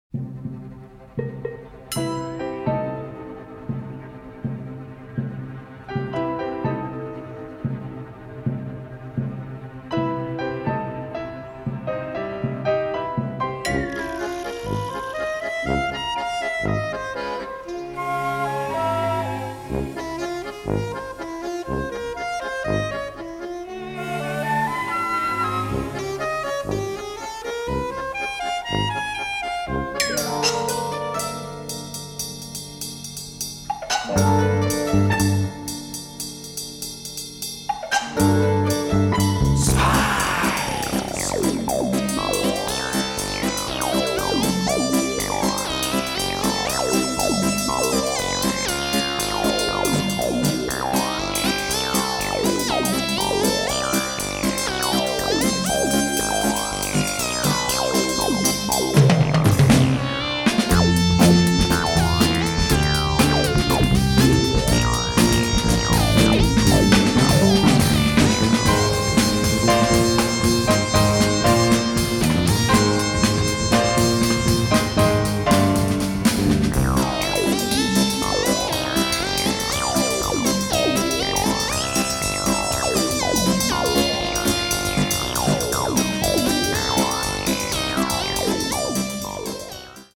jazz-influenced